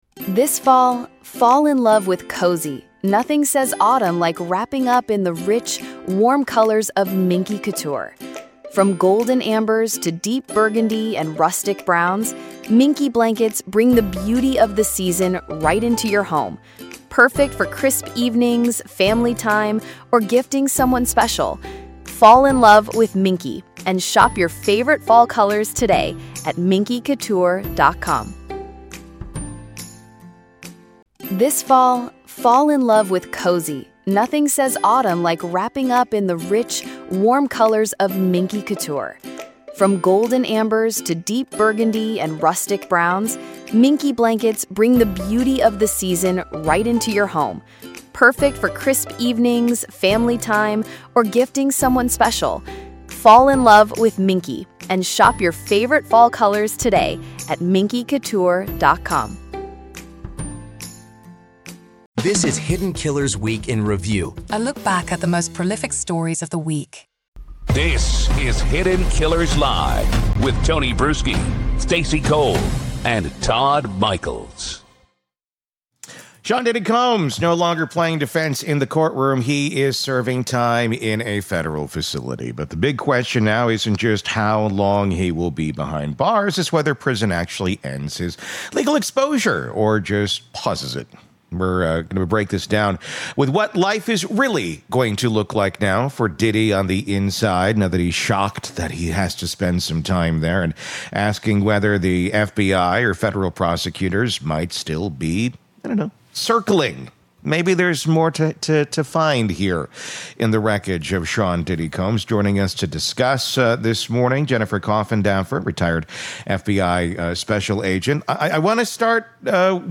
Hidden Killers Live! Daily True Crime News & Breakdowns / FBI Insider on Diddy’s Prison Life & d4vd’s Tesla Case: Is More Coming?